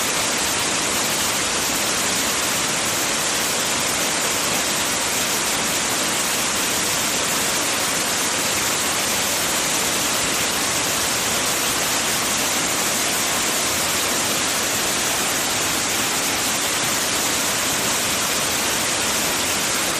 River Stream
River Stream is a free nature sound effect available for download in MP3 format.
335_river_stream.mp3